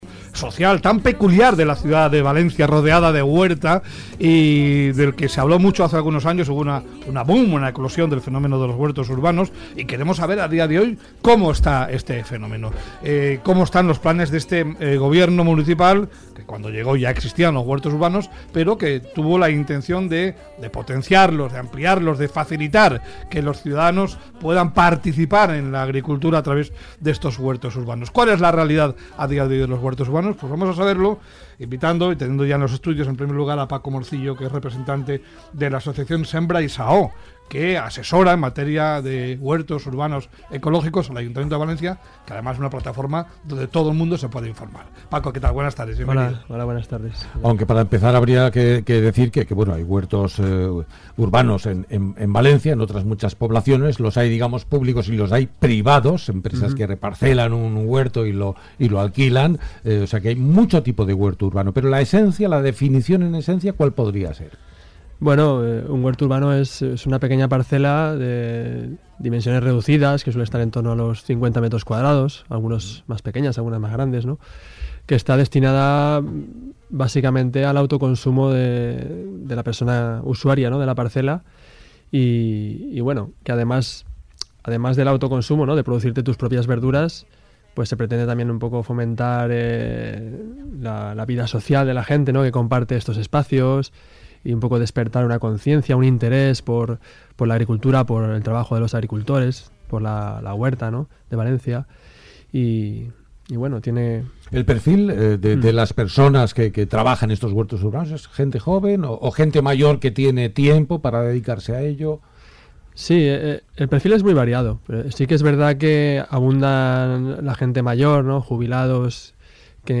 Entrevista al programa Hoy por Hoy Locos por Valencia. Cadena Ser. 06/05/2019.